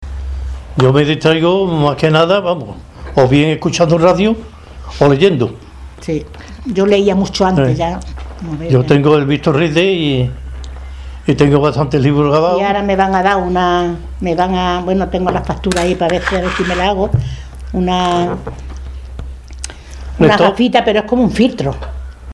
Les brindamos una charla amigable, hablando del pasado, del presente y del futuro; de los problemas, como la soledad, que vencen sobre todo gracias a la oportunidad de la lectura,